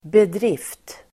Uttal: [bedr'if:t]